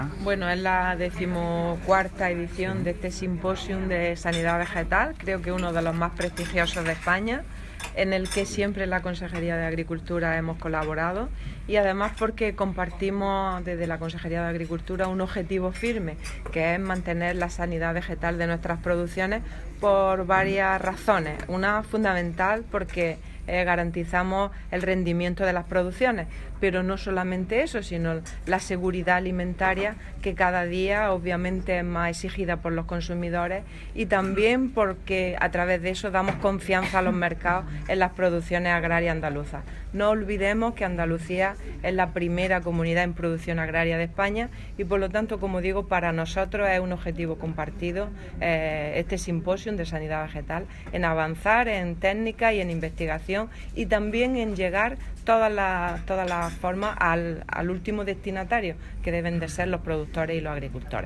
La consejera Carmen Ortiz, durante su intervención en el 14 Symposium de Sanidad Vegetal.
Declaraciones Carmen Ortiz sobre el simposio